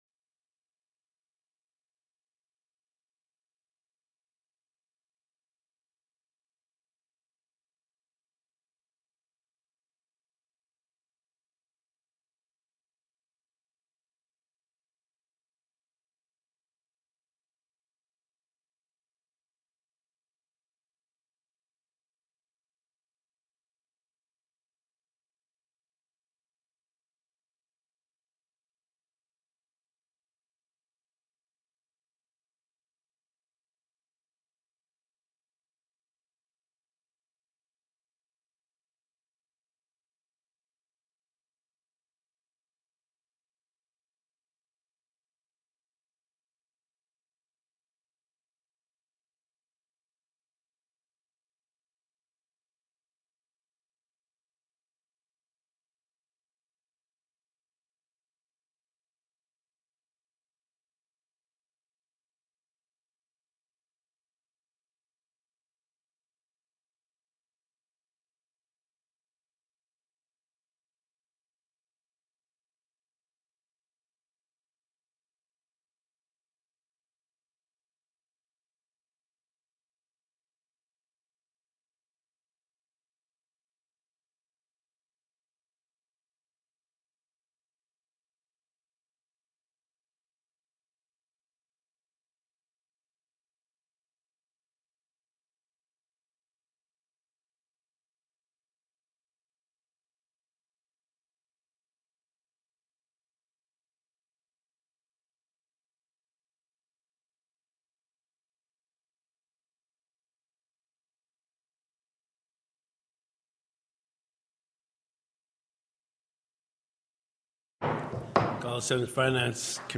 The audio recordings are captured by our records offices as the official record of the meeting and will have more accurate timestamps.
HB 23 STATE COMMISSION FOR CIVIL RIGHTS TELECONFERENCED Heard & Held + -- Invited & Public Testimony --